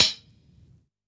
Index of /musicradar/Kit 12 - Vinyl
CYCdh_VinylK5-ClHat01.wav